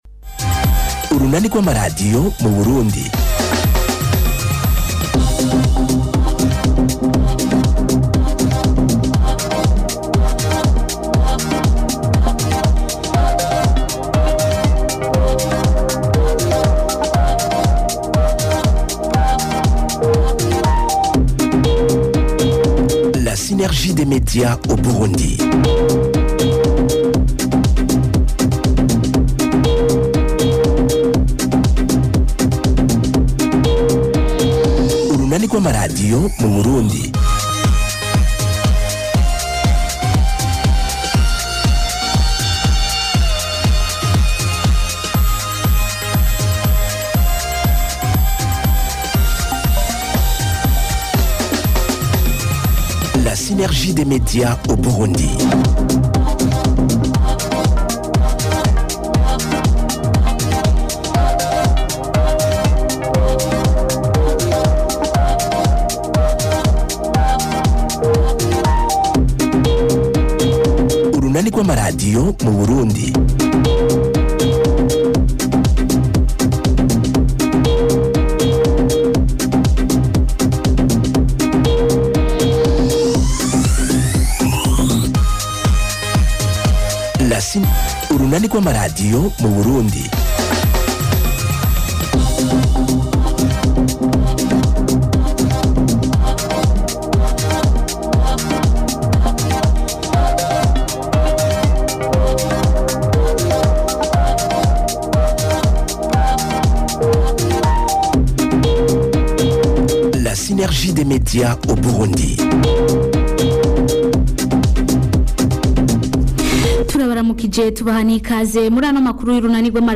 Amakuru y’urunani rw’amadiyo yo ku wa 26 Ruhuhuma 2026